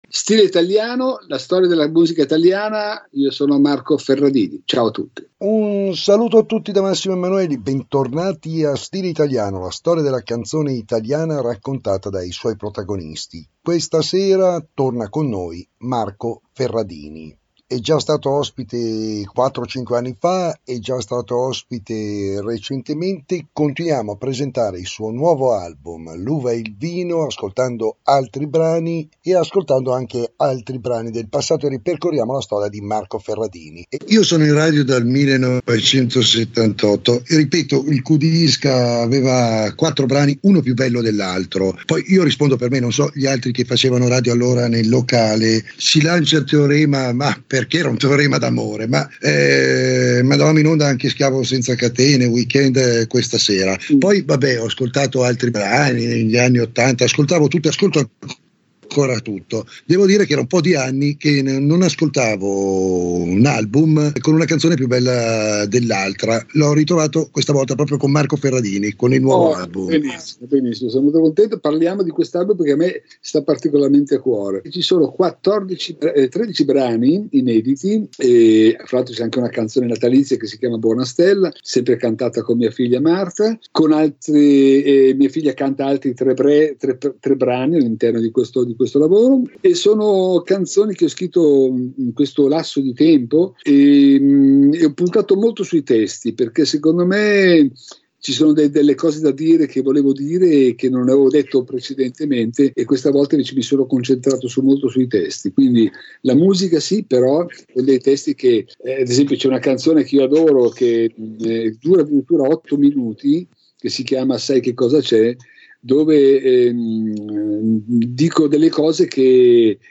marco-ferradini-2020-2-solo-parlato.mp3